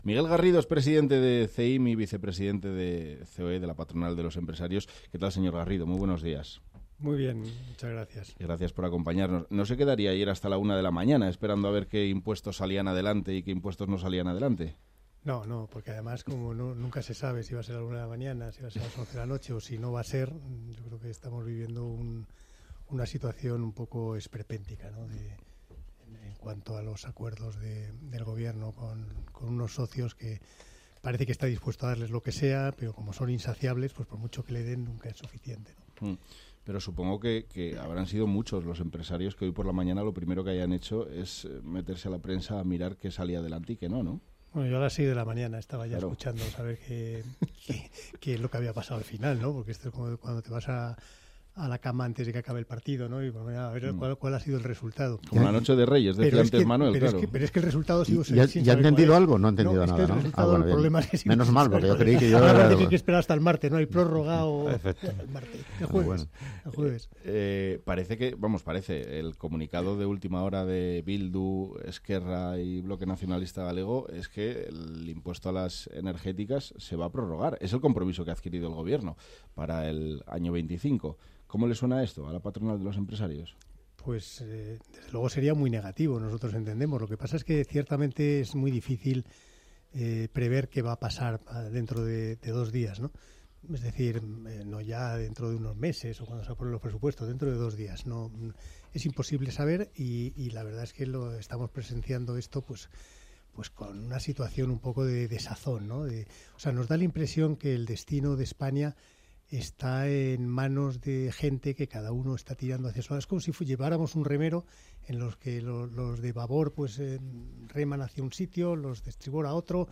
entrevista en Onda Madrid